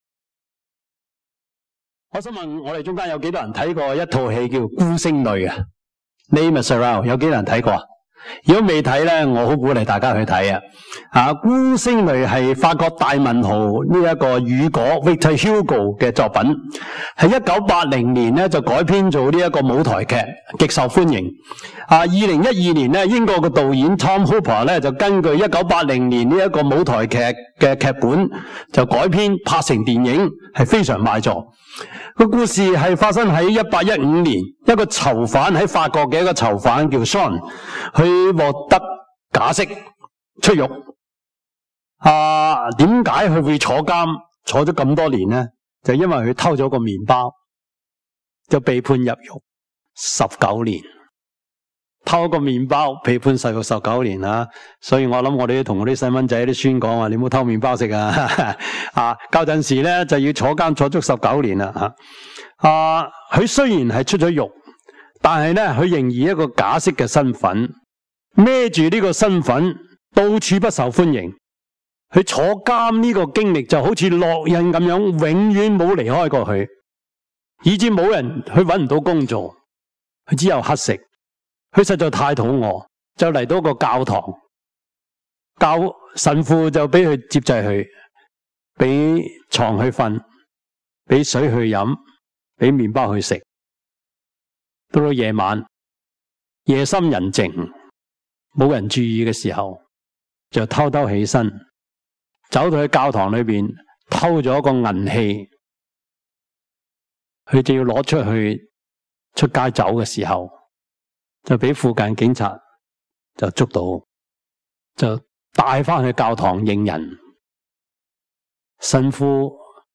Service Type: 主日崇拜
Topics: 主日證道 « 苦罪懸謎 勞苦與喜樂 »